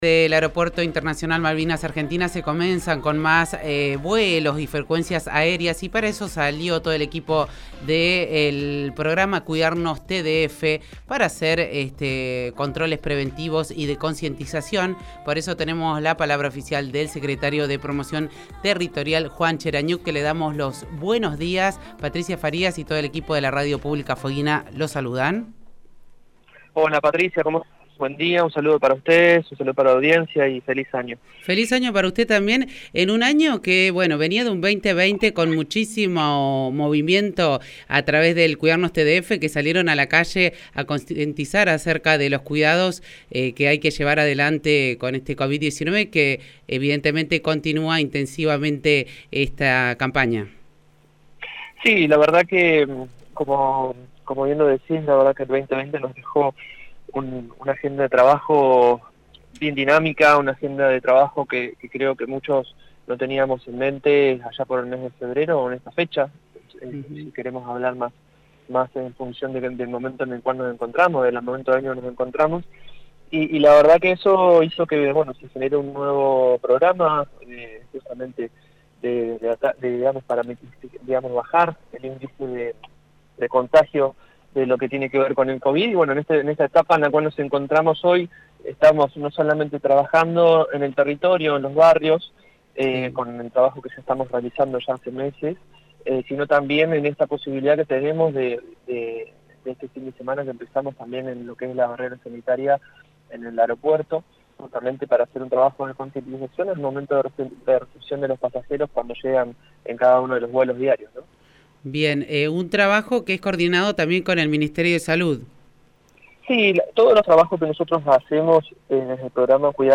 El secretario de Promoción Territorial, Juan Cherañuk, en diálogo con la Radio Pública Fueguina , resaltó que el trabajo que desarrollan los Agentes sanitarios del Programa Cuidarnos TDF forman parte de los controles preventivos y concientización que se realizan como parte de la barrera sanitaria en el Aeropuerto Internacional Malvinas Argentinas de Ushuaia.